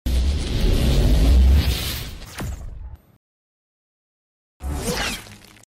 7. Звуковой эффект суперскорости (2 варианта)
superspeed-effect-.mp3